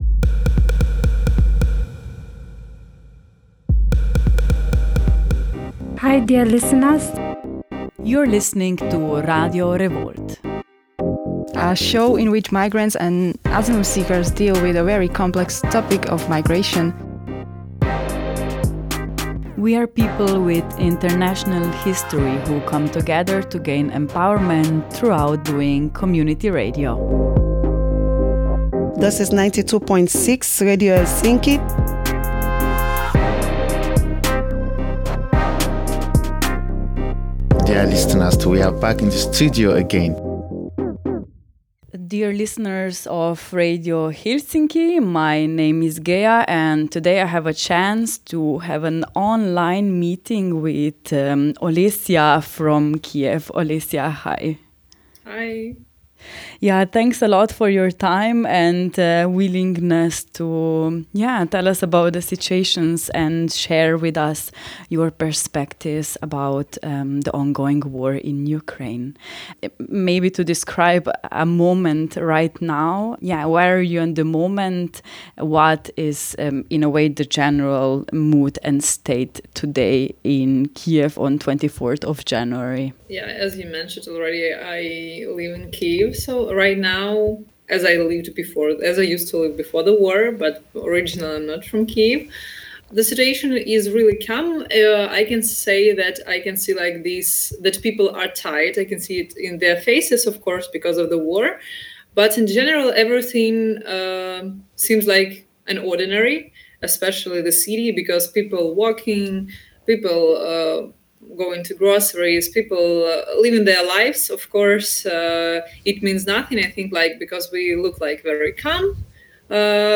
In this week’s interview